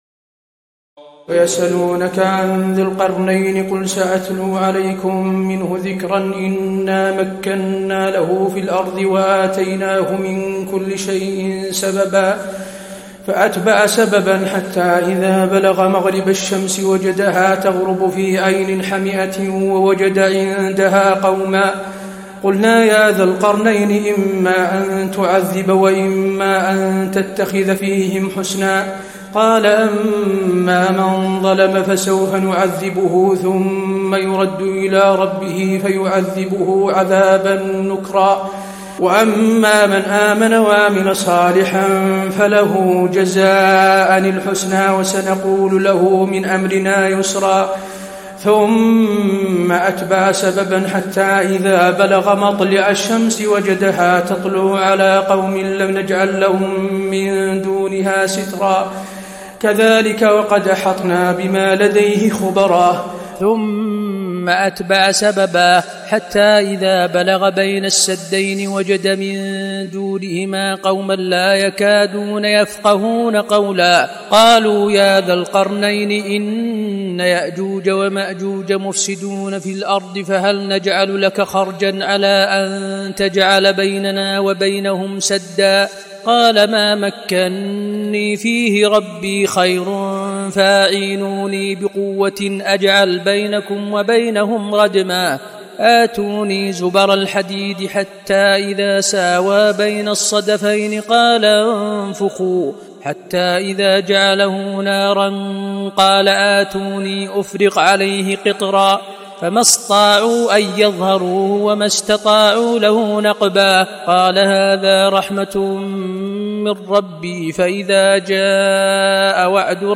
تراويح الليلة الخامسة عشر رمضان 1435هـ من سورتي الكهف (83-110) و مريم كاملة Taraweeh 15 st night Ramadan 1435H from Surah Al-Kahf and Maryam > تراويح الحرم النبوي عام 1435 🕌 > التراويح - تلاوات الحرمين